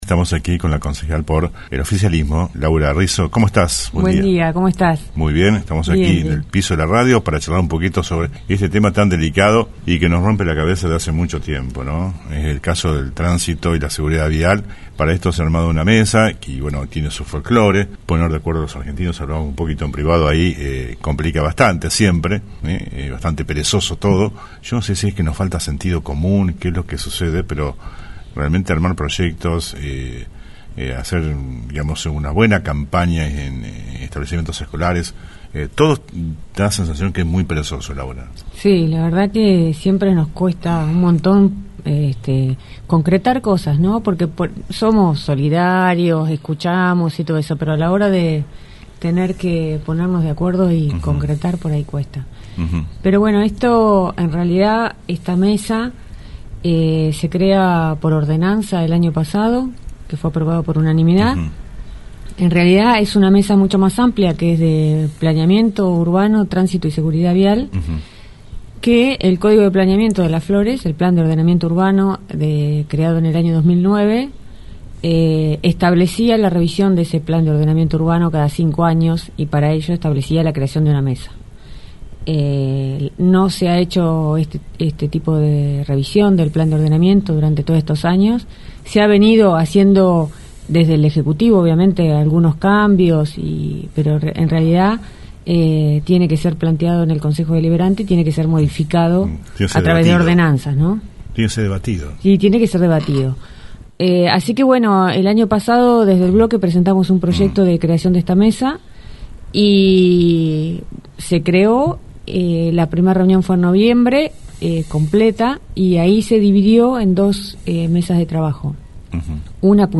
ENTREVISTA COMPLETA A LAURA RISSO